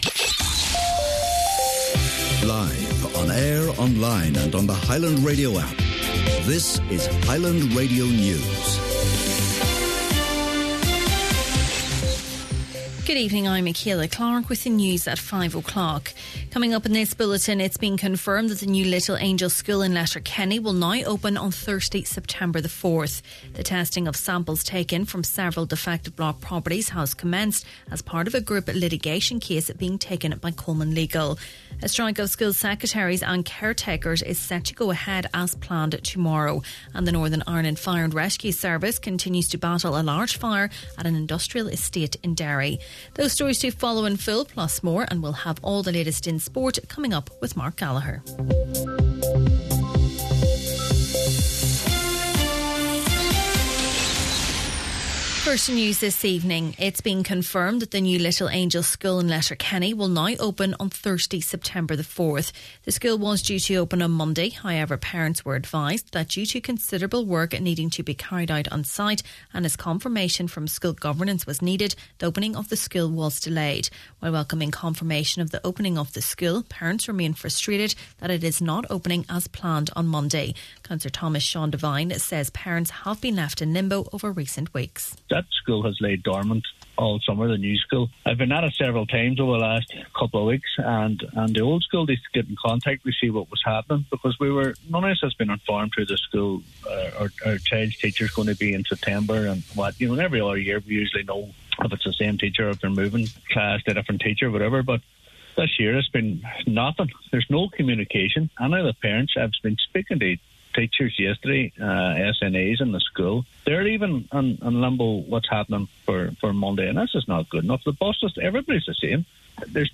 Main Evening News, Sport and Obituaries – Wednesday, August 27th